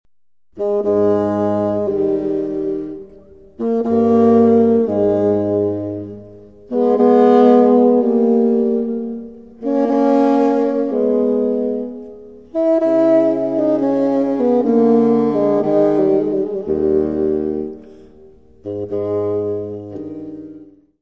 fagote
Music Category/Genre:  Classical Music
Sonate nº5 opus 3 pour deux bassons en la mineur
Cantabile.